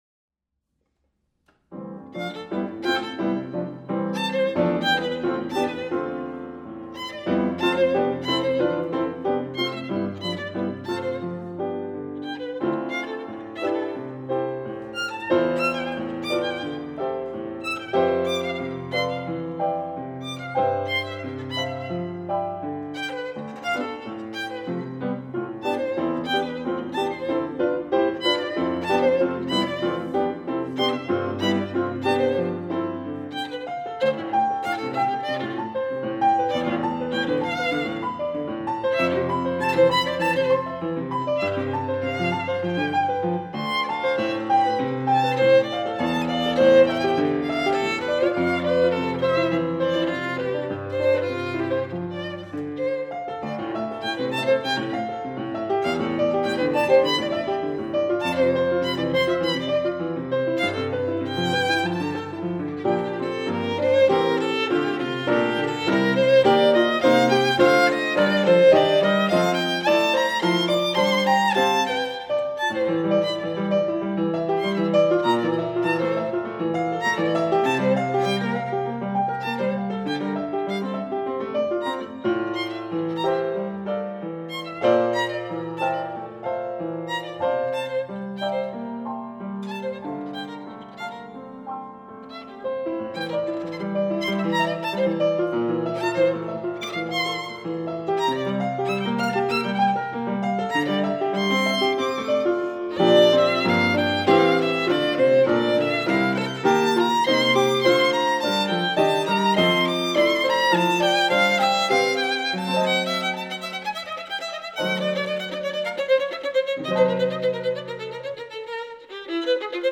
Violine